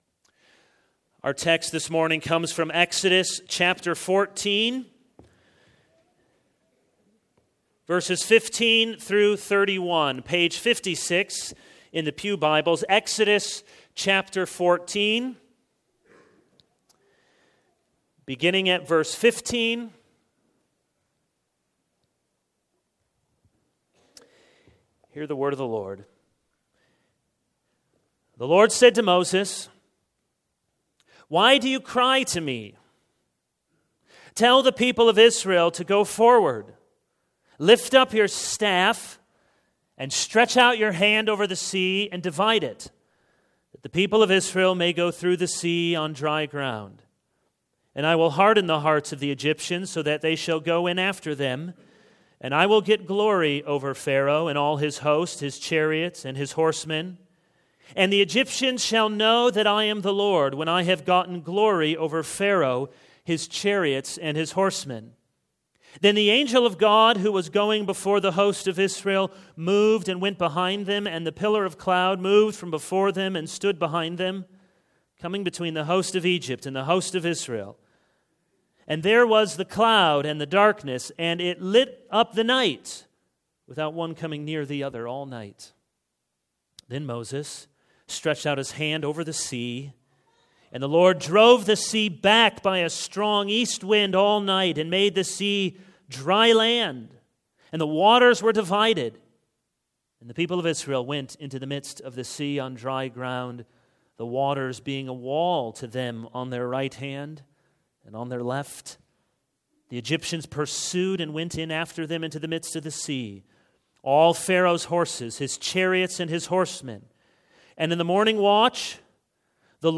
This is a sermon on Exodus 14:15-31.